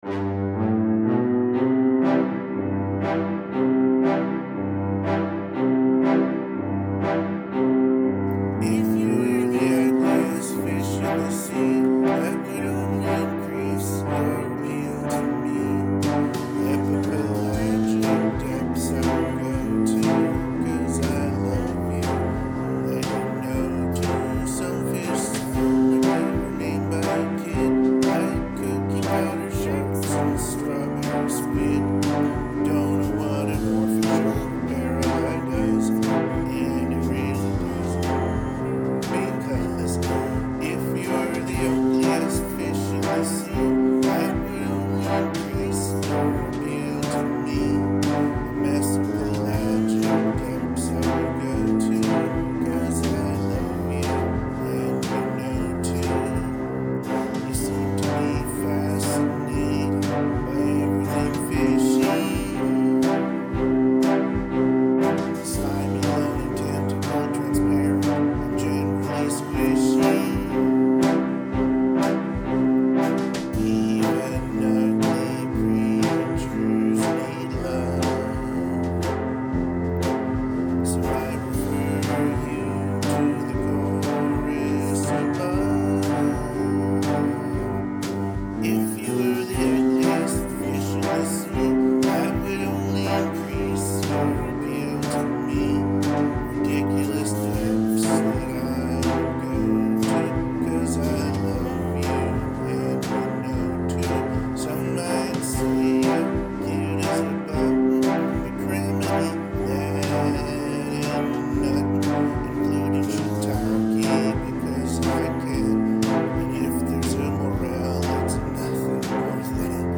TheUgliestFish-singalong-karaoke.mp3